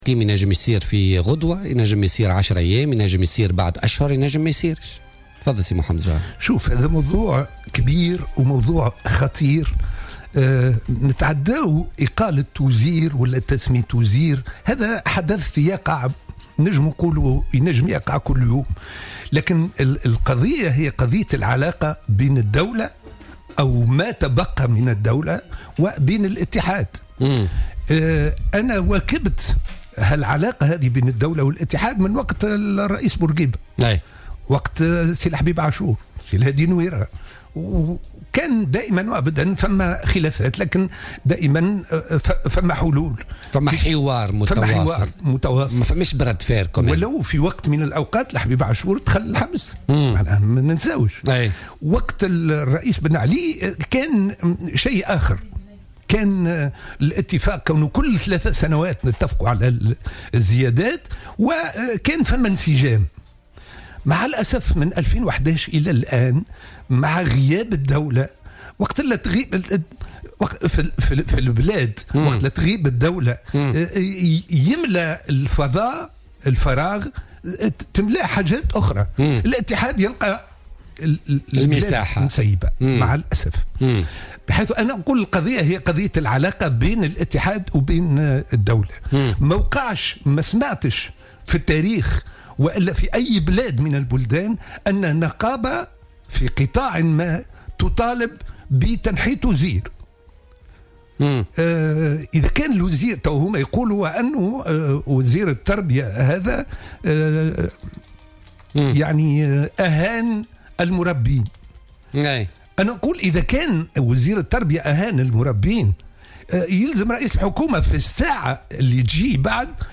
قال رئيس حزب الوطن محمد جغام ضيف بولتيكا اليوم الإثنين 1 ماي 2017 في تعليق على قرار إقالة ناجي جلول إن هذه المسألة خطيرة وكبيرة جدا.